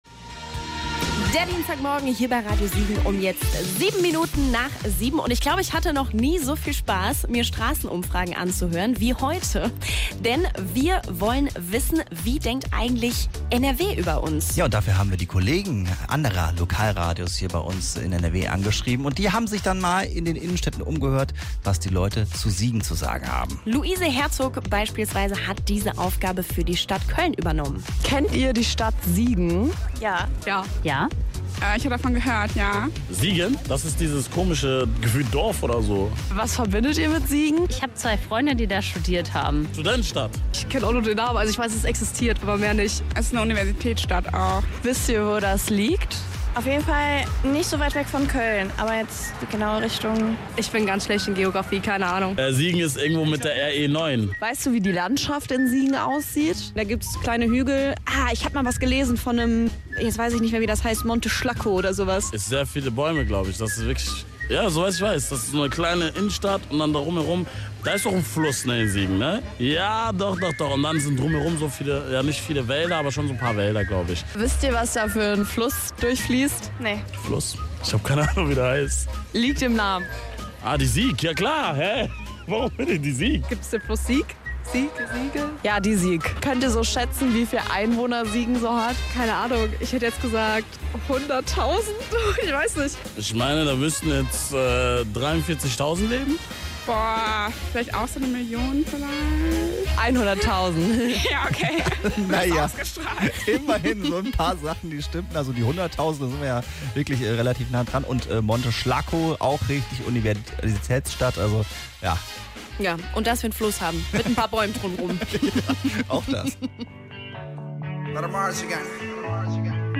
Mit Straßenumfragen aus Köln, Dortmund, Essen, Münster und Leverkusen.